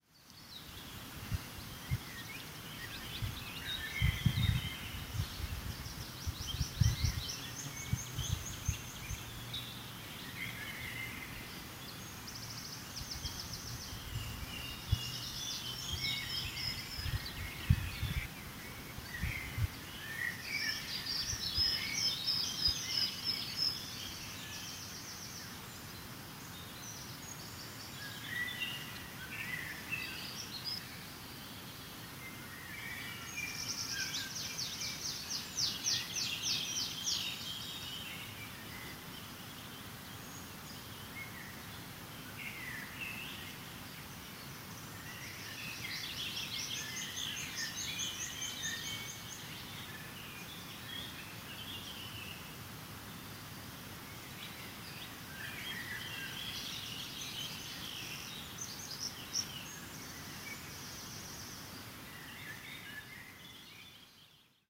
The woods on a warm day
In the still and humid air, any sunshine would be uncomfortable, but in the event we saw little of the sun, and it was certainly very pleasant under the trees on the eastern slopes of Brown Clee.
brown-clee.mp3